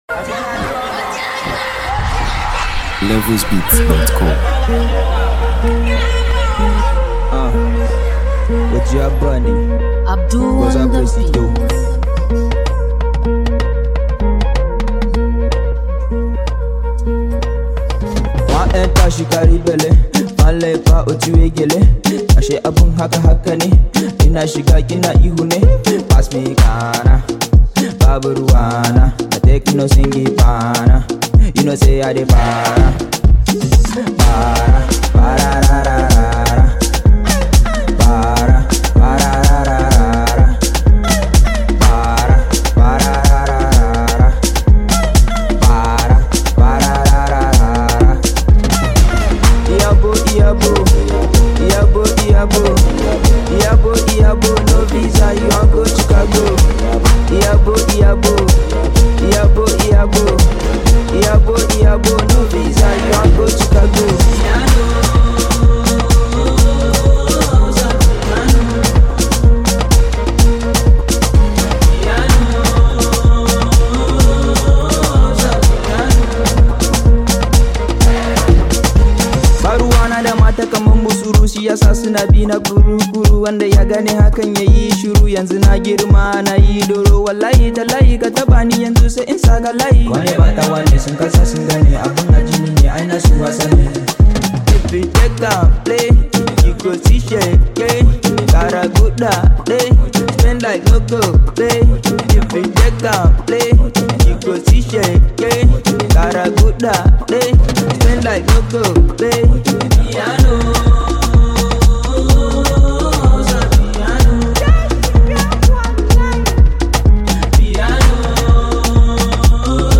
Blending infectious rhythms with engaging melodies